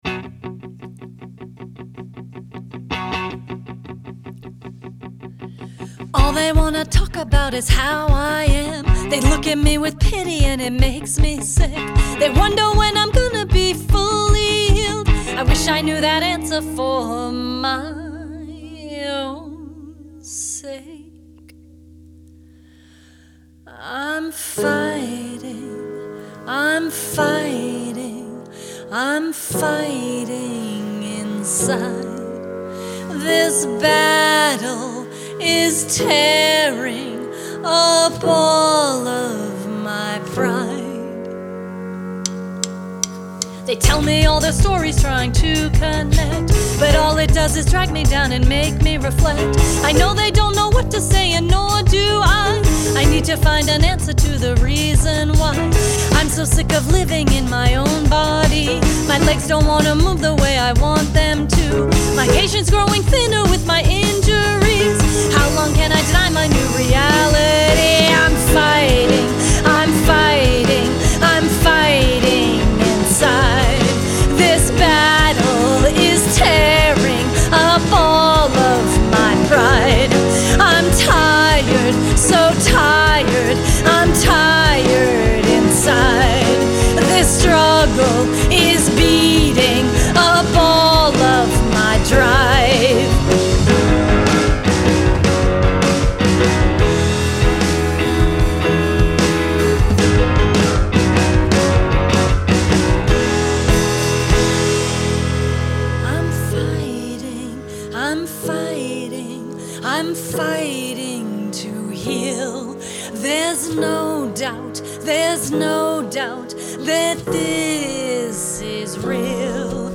Vocalist
Guitar & Base
Piano
Drums